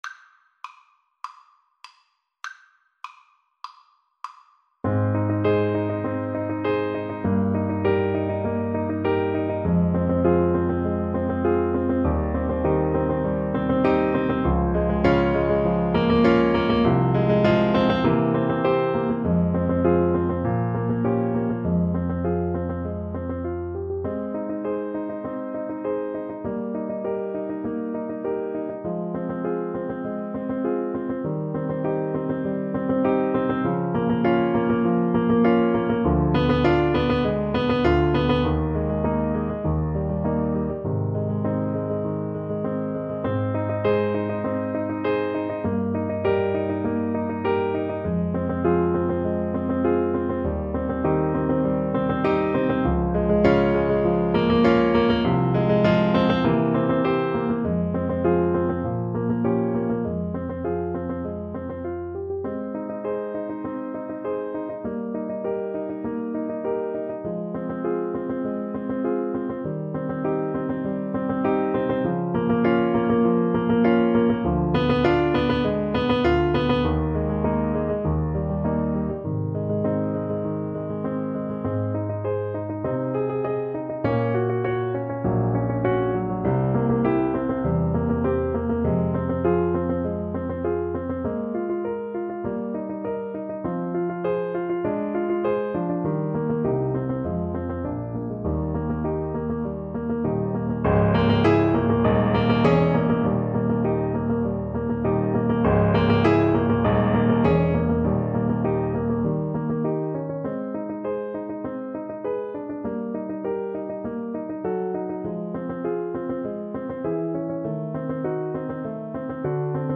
Play (or use space bar on your keyboard) Pause Music Playalong - Piano Accompaniment Playalong Band Accompaniment not yet available transpose reset tempo print settings full screen
Allegro moderato (View more music marked Allegro)
Ab major (Sounding Pitch) (View more Ab major Music for Trombone )
Classical (View more Classical Trombone Music)